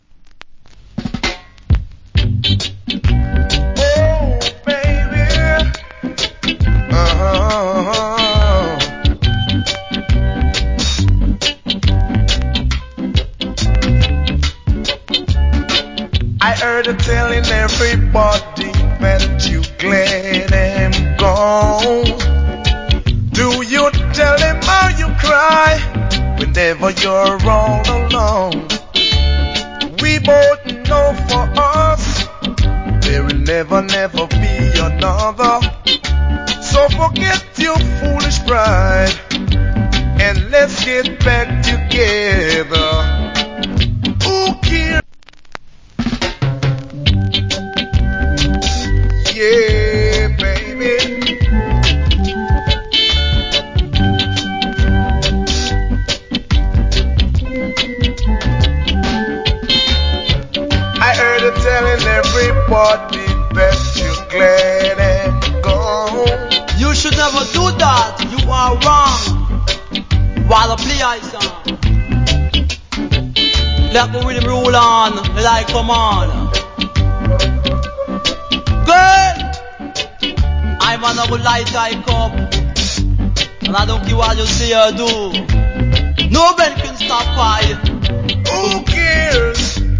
Nice Reggae Vocal.